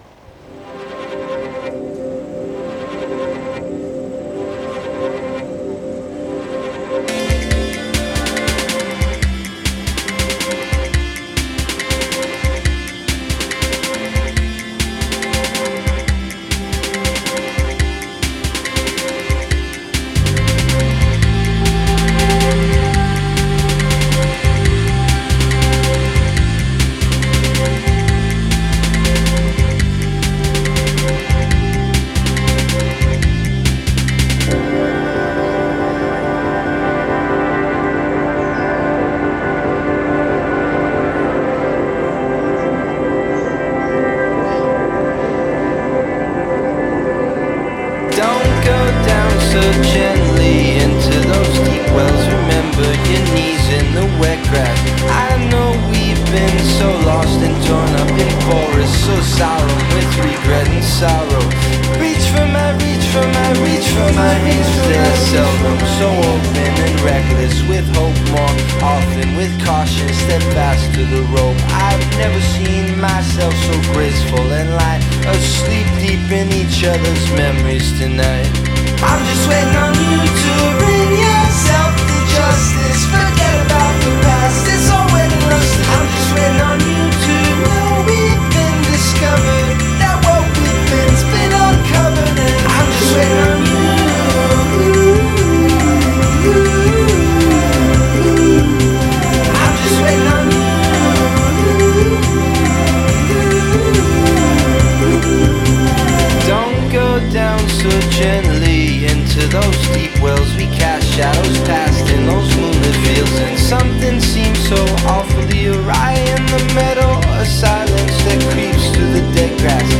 Love the summer sound